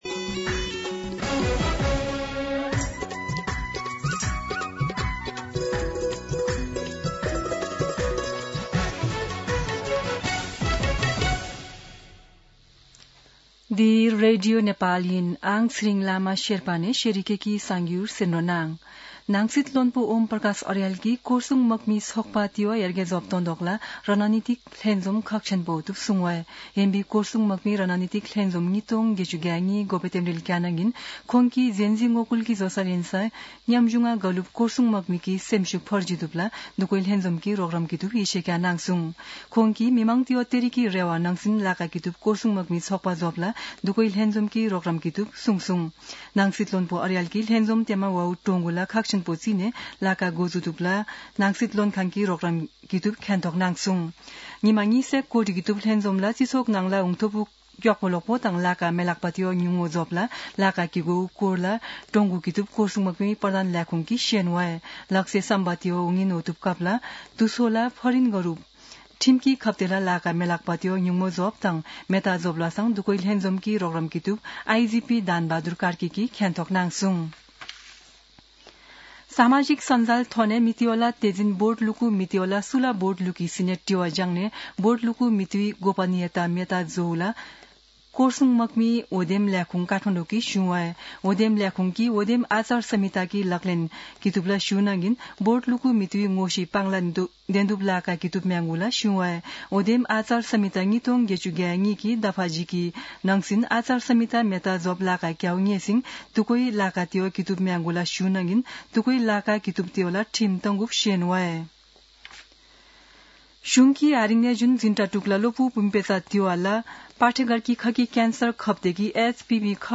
शेर्पा भाषाको समाचार : २५ माघ , २०८२
Sherpa-News-25.mp3